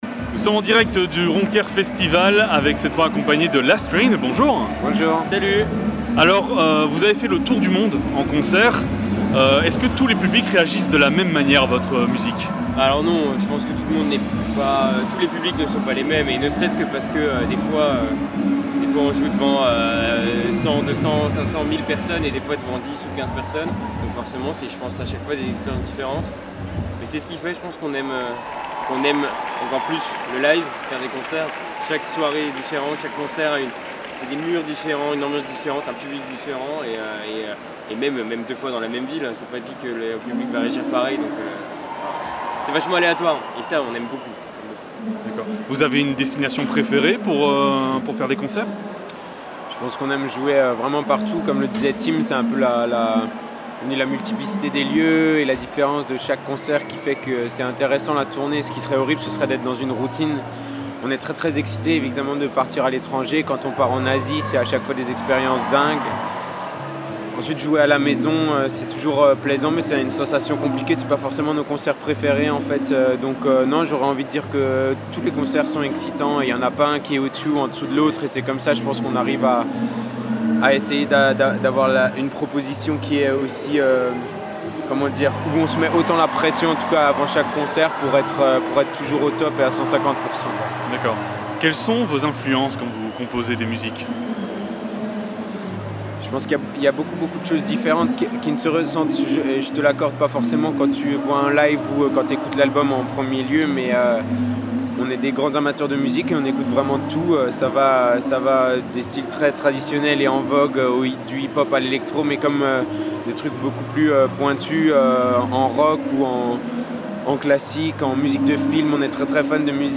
Ronquières Festival 2019 - Rencontre avec Last Train